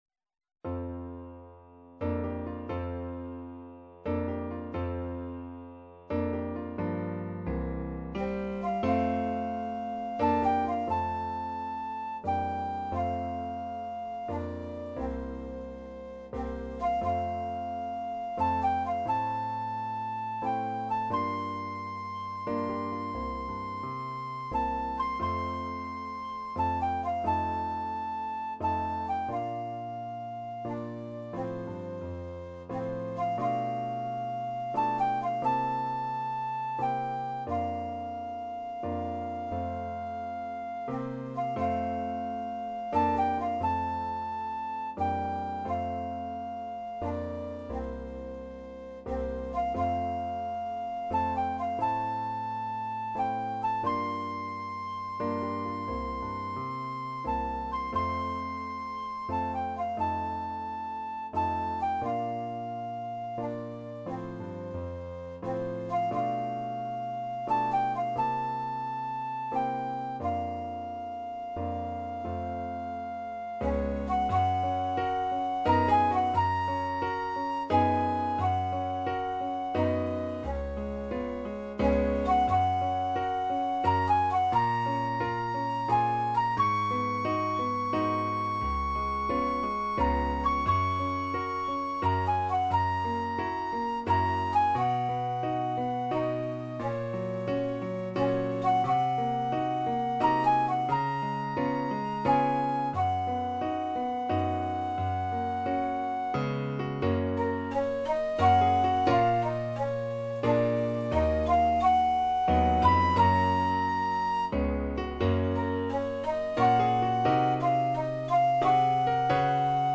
Besetzung: Instrumentalnoten für Flöte